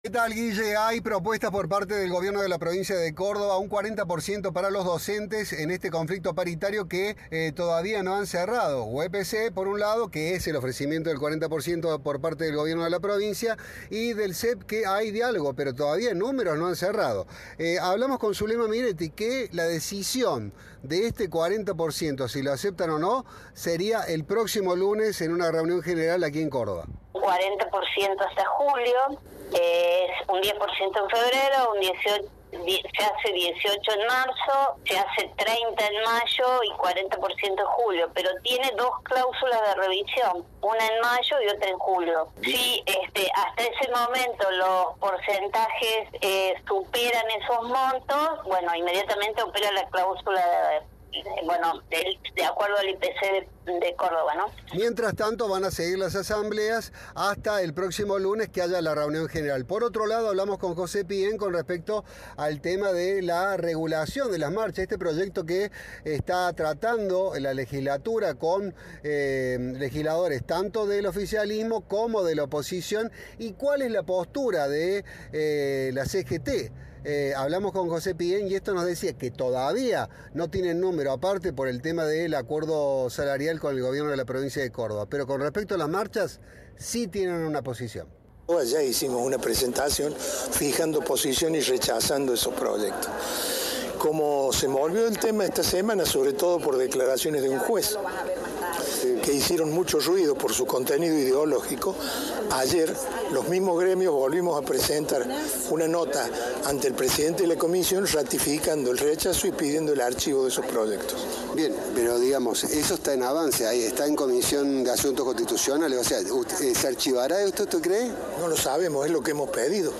Informe
Entrevista